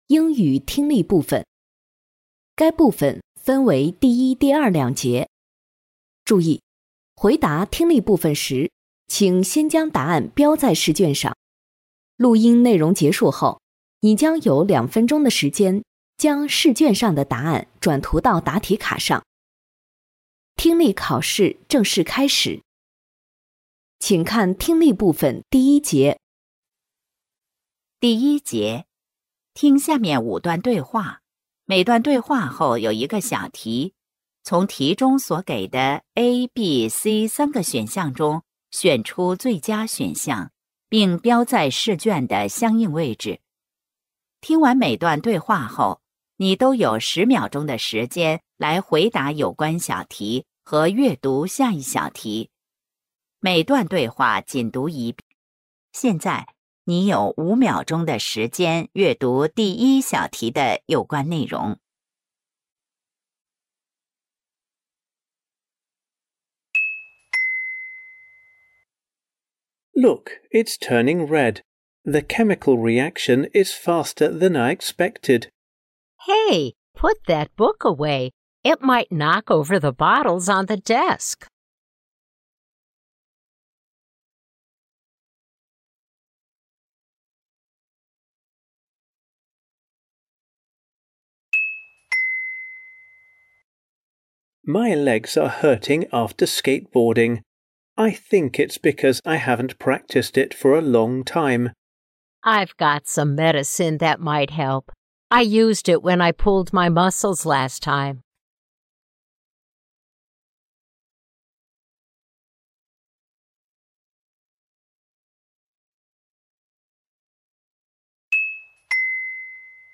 成都树德中学高2026届高三上学期11月阶段测试英语听力.mp3